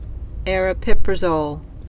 (ārə-pĭprə-zōl)